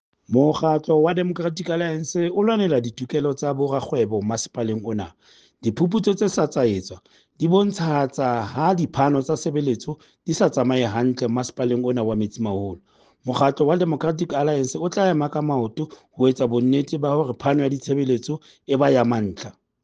Sesotho soundbites by Cllr Stone Makhema.